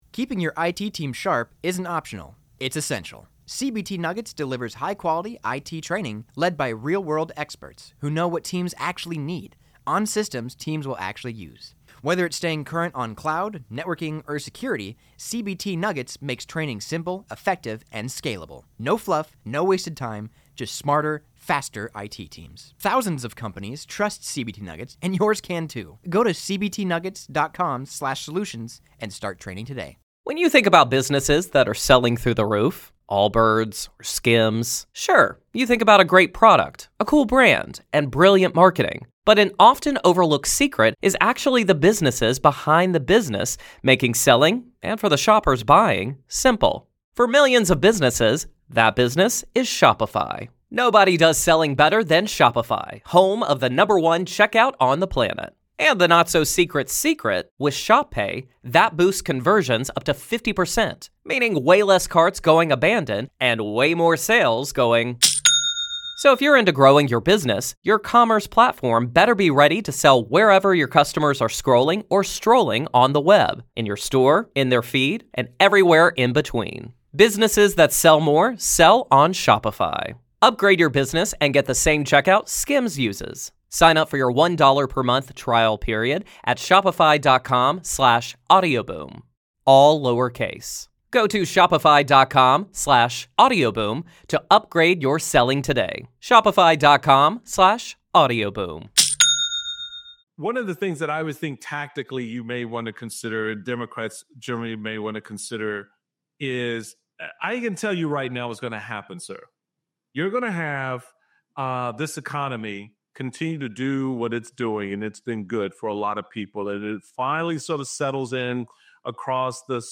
House Minority Leader Hakeem Jeffries speaks with Michael Steele about reaching bipartisanship and the unseriousness of Marjorie Taylor Greene leading the DOGE subcommittee.